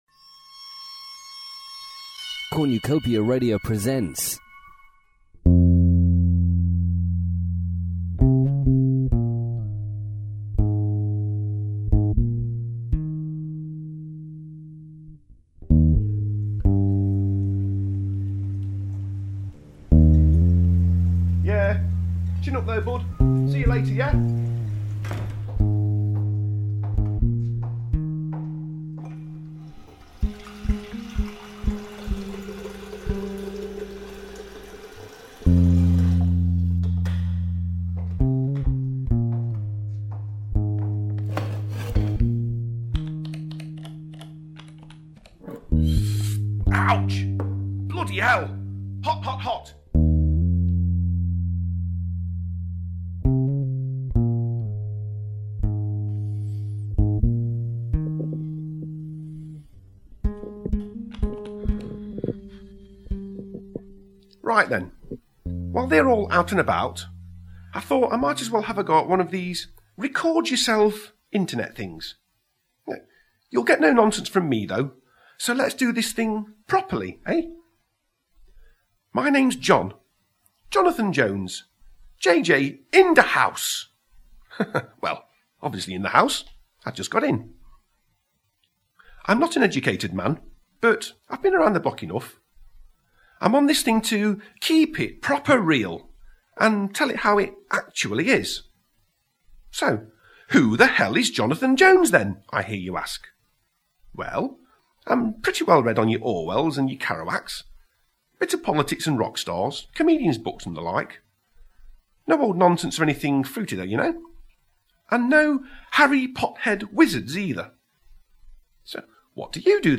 It also features the additional voices of